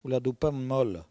Patois - archive
Catégorie Locution